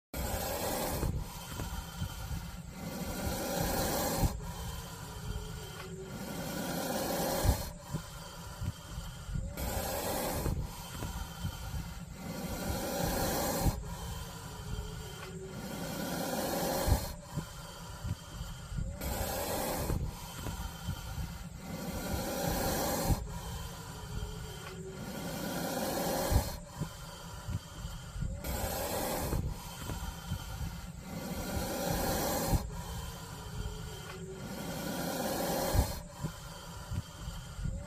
Tiếng Rắn Hổ Mang kêu
Thể loại: Tiếng động vật hoang dã
Âm thanh này thường là tiếng rít, tiếng phì phì, hoặc tiếng thở gằn phát ra khi rắn cảm thấy bị đe dọa, nhằm cảnh báo hoặc đe dọa kẻ thù. Âm thanh có thể mang lại cảm giác rợn người, hồi hộp, hoặc ghê rợn...
tieng-ran-ho-mang-keu-www_tiengdong_com.mp3